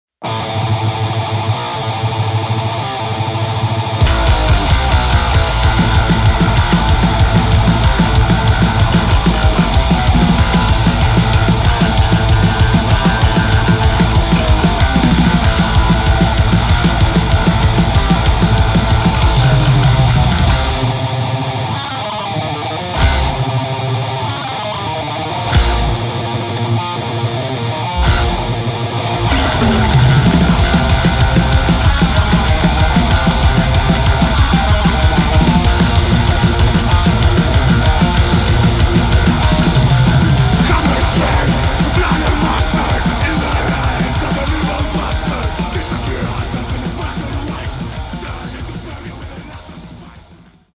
37 minutes of relentless metal from this Greek trio-
Black Thrash
Greek Black Speed Metal